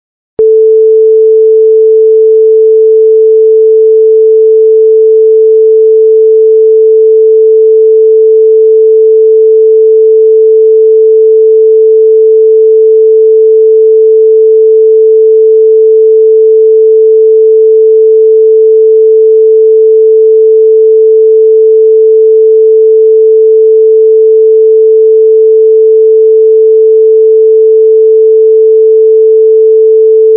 Камертон 110 герц